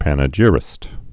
(pănə-jĭrĭst, -jīrĭst)